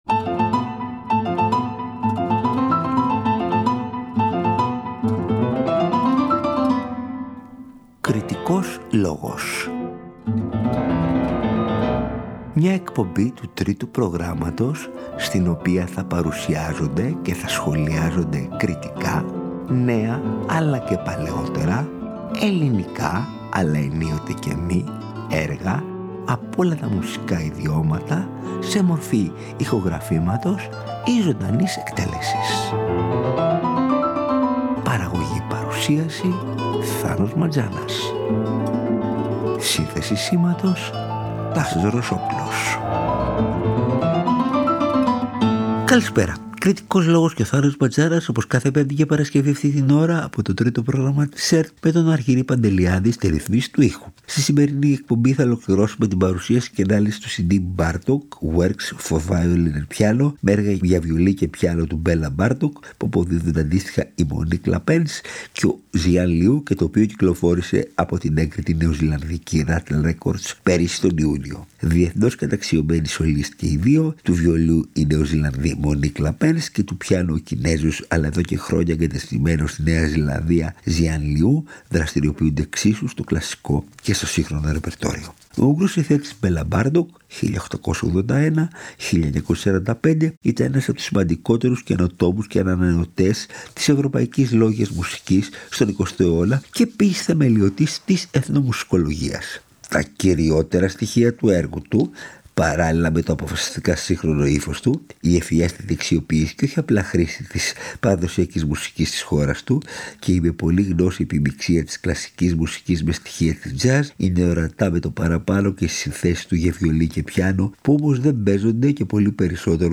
Τα έργα του Μπέλα Μπάρτοκ για βιολί και πιάνο – Μέρος ΄Β